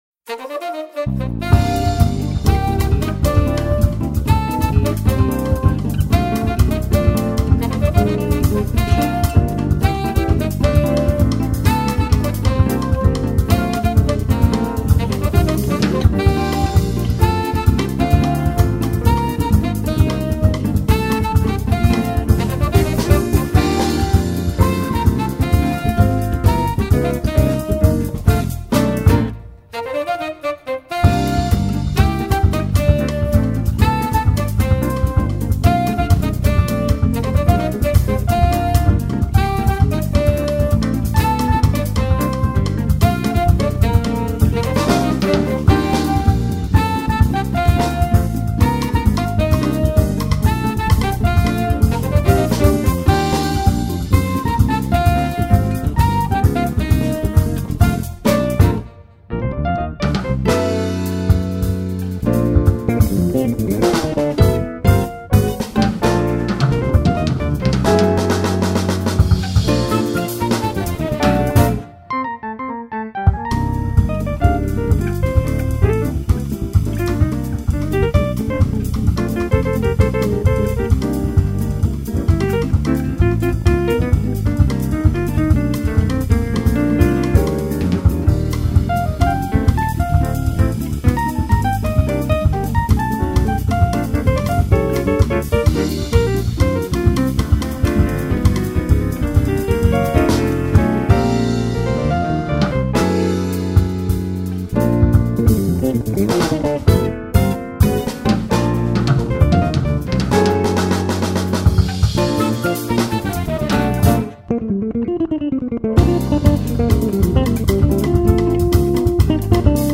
2537   02:58:00   Faixa: 5    Jazz